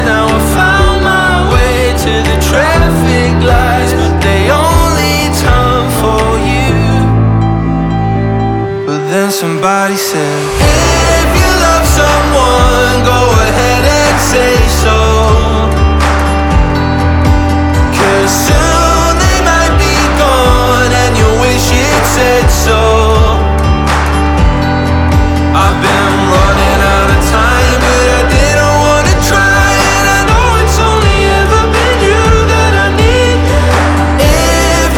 2024-09-20 Жанр: Рок Длительность